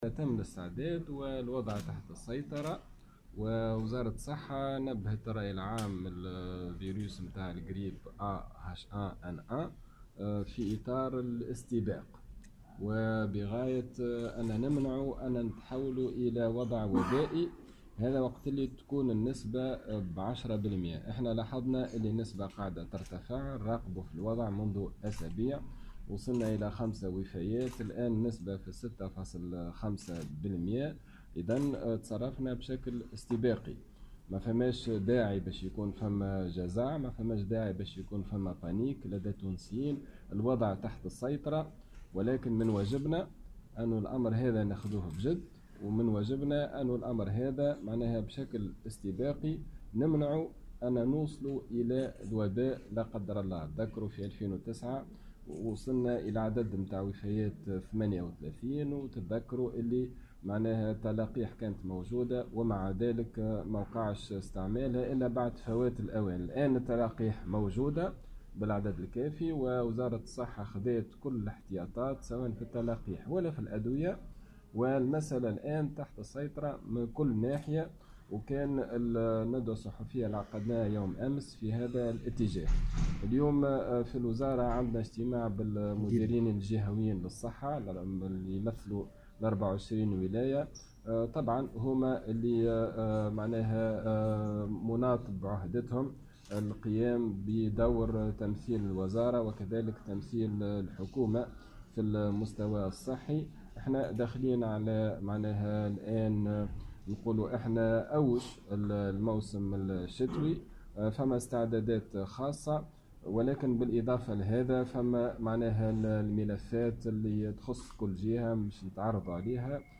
وأوضح في تصريح لمراسلة "الجوهرة اف أم" أن الوزارة على أتم الاستعداد وأن تحركها يأتي في اطار الاستباق تجنبا لبلوغ وضع وبائي، وذلك على هامش اجتماع عقده مع المديرين الجهويين للصحة في ولايات الجمهورية الـ 24.